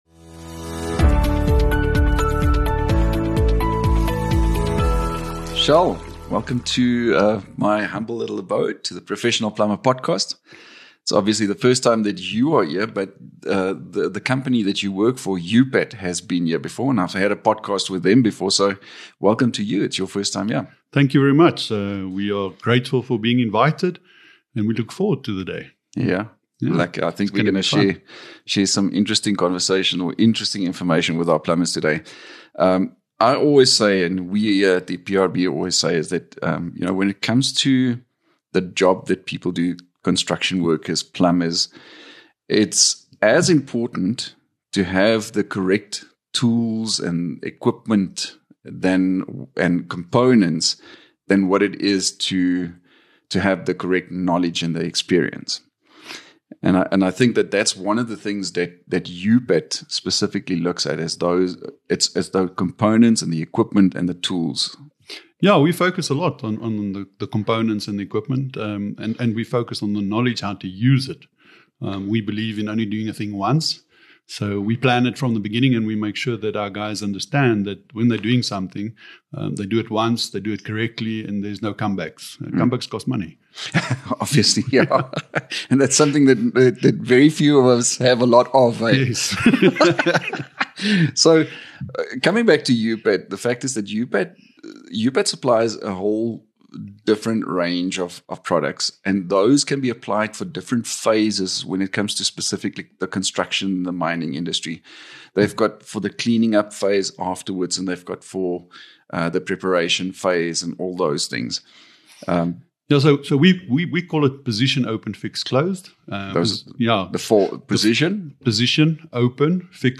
The Plumbing Industry Registration Board (PIRB) is a trusted professional body, as recognised by the South African Qualifications Authority (SAQA), that works proactively to promote better plumbing practices in South Africa. In these podcast episodes we will have interesting and informative conversations with industry experts, PIRB personnel, and also with other invited guests, with the intent to serve our registered plumbers through yet another exciting and convenient platform.